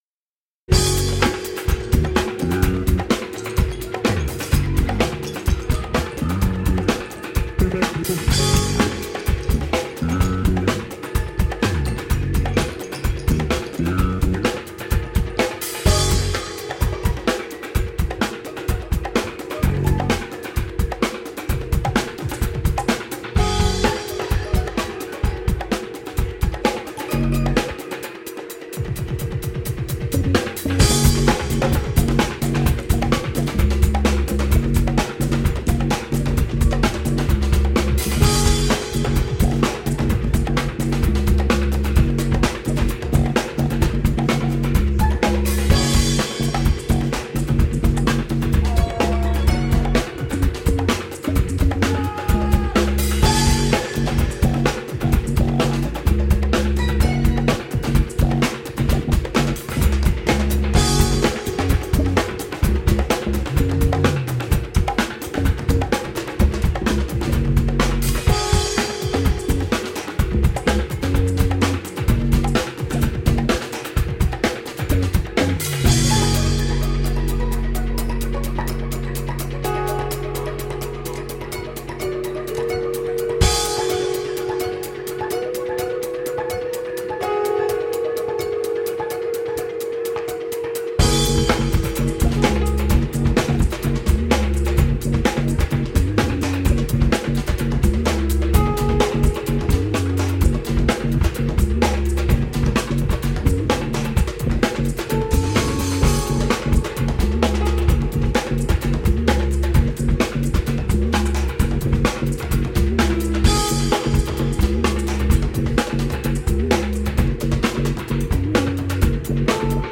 Live, improvised electronica.